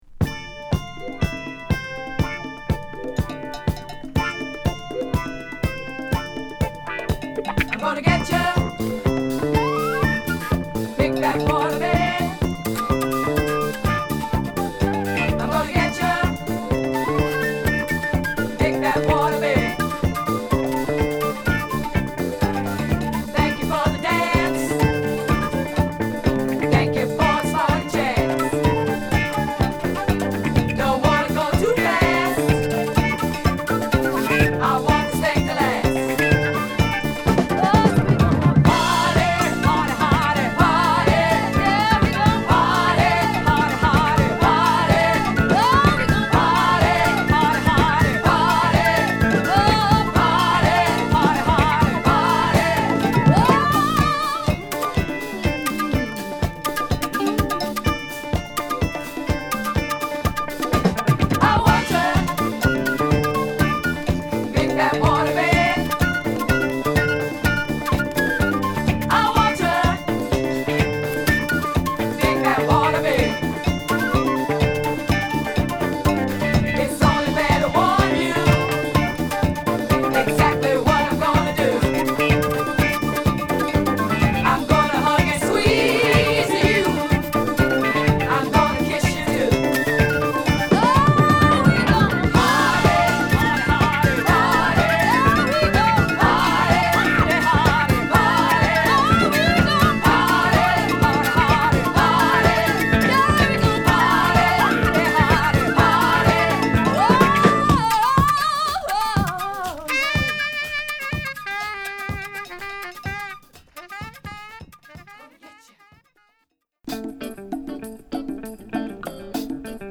50年代中期から多くの作品を残すフルート奏者